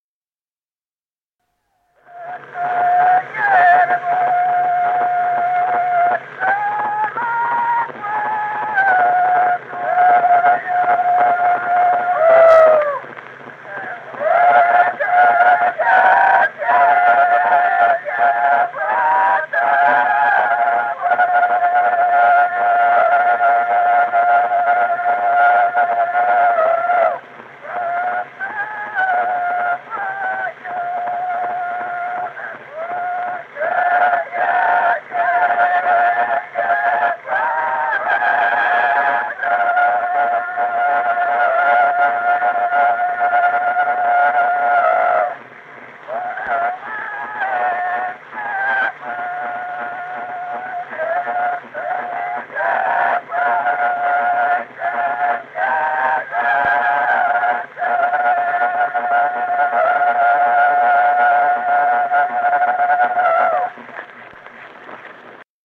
Народные песни Стародубского района «Не стой, вербочка», весняная девичья.
с. Остроглядово.